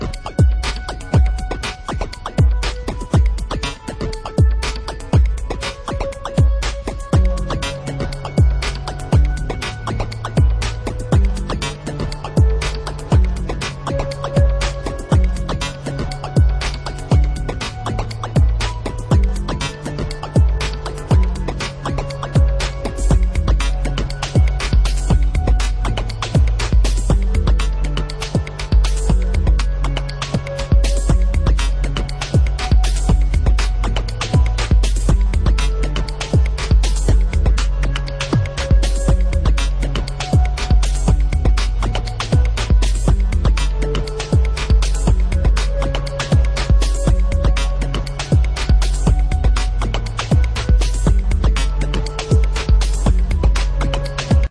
A cool mixup of beats and electronica.
Techno